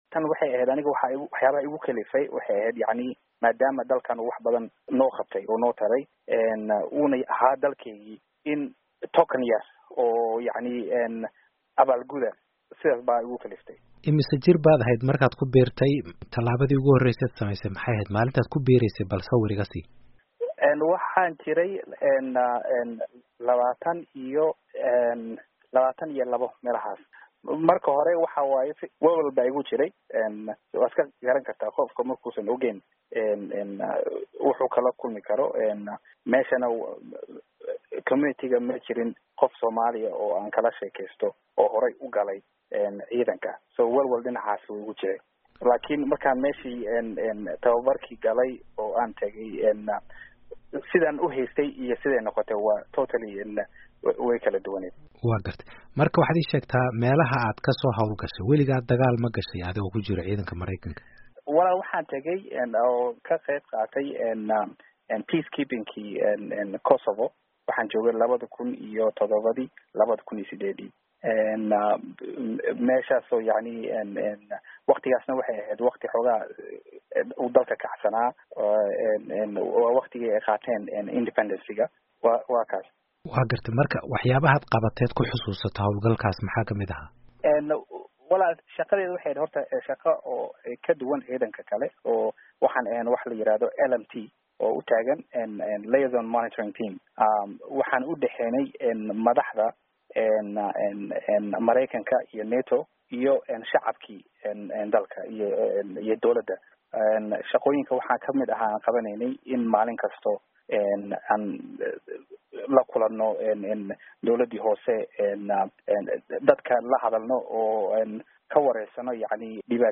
Wareysi: Askari hore